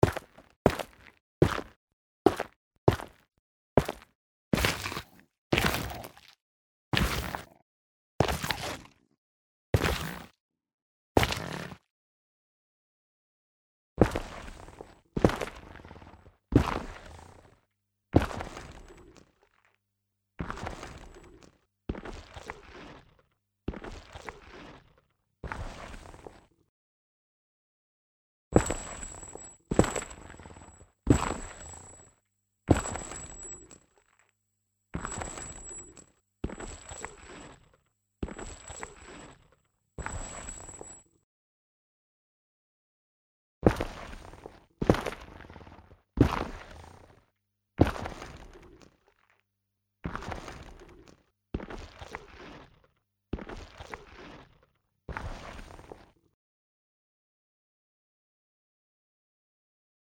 A high pitched noise is produced when you try to break or when you break Netherrack.
1. the clean breaking sounds from the assets folder
2. the breaking sounds from in-game with the sound problem
3. the breaking sounds  **  from in-game with the sound frequency gained
4. the breaking sounds from in-game with sound frequency cut-off as it should be.